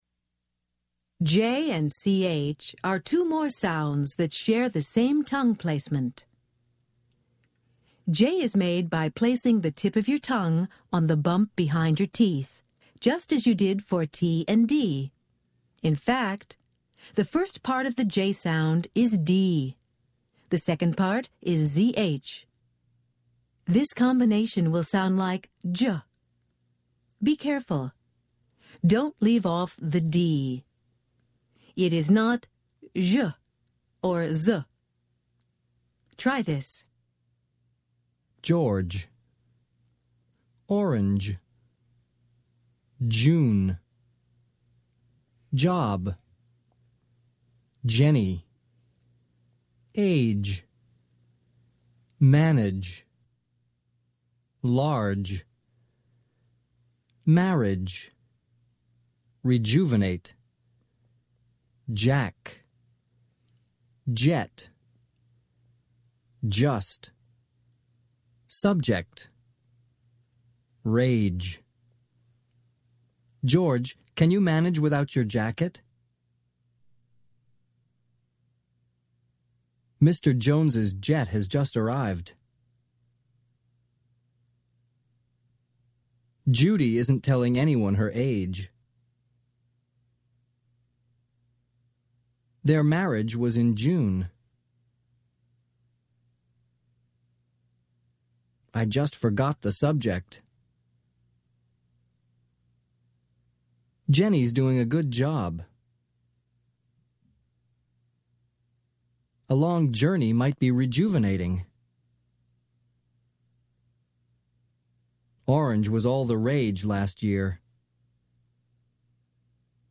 American Phonics Lesson 25 - Consonant /j/